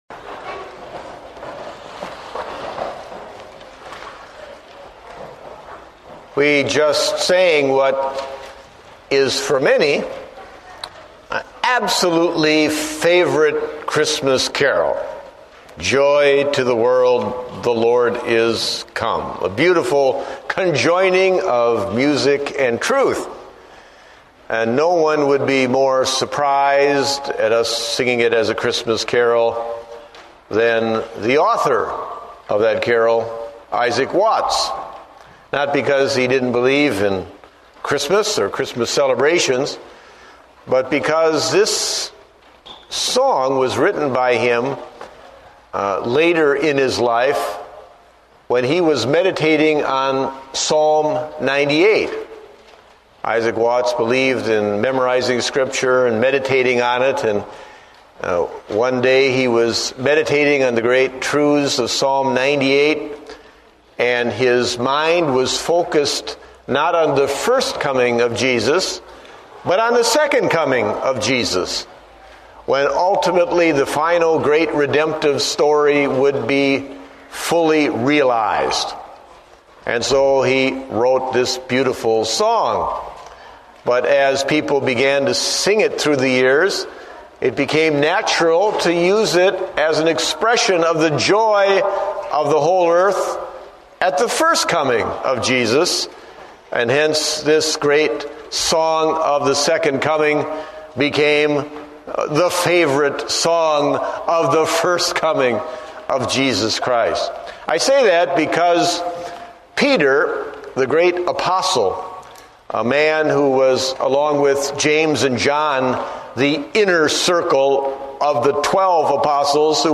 Date: December 7, 2008 (Morning Service)